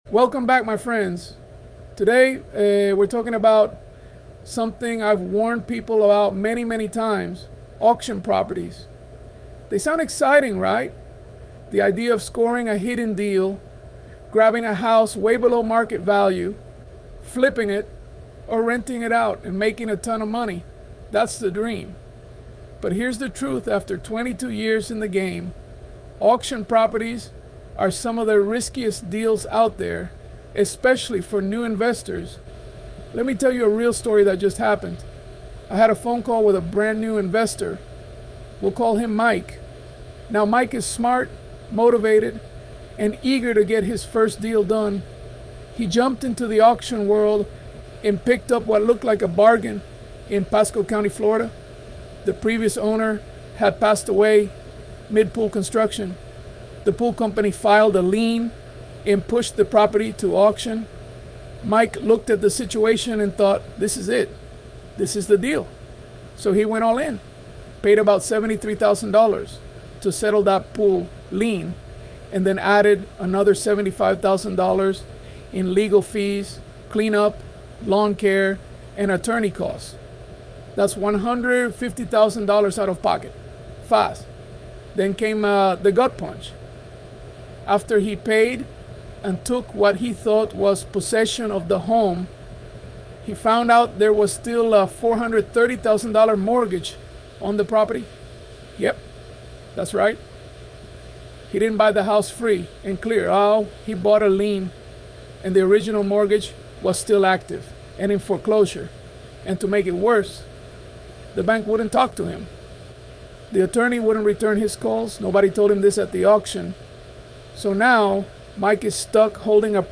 ElevenLabs_Welcome_back_my_friends._Today_were_talking.-3.mp3